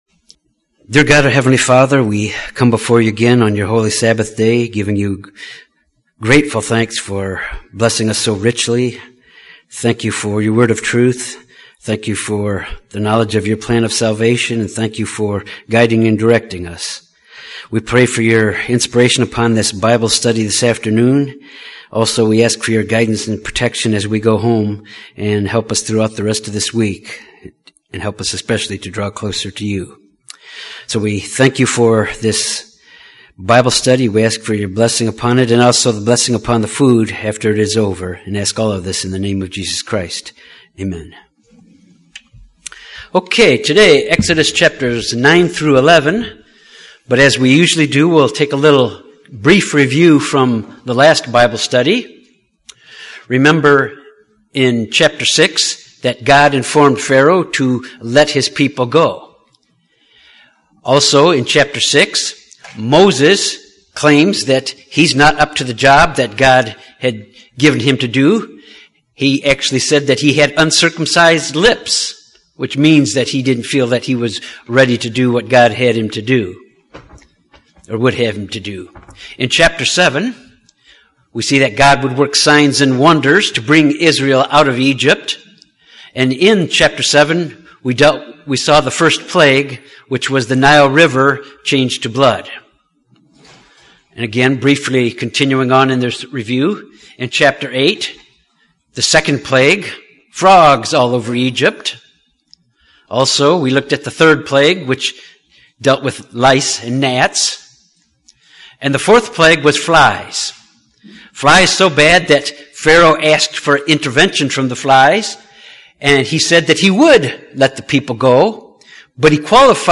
This bible study examines the last plagues on Egypt that God used to show His power and authority over all of mankind and their gods.